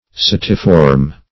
Search Result for " setiform" : The Collaborative International Dictionary of English v.0.48: Setiform \Se"ti*form\, a. [Seta + -form: cf. F. s['e]tiforme.]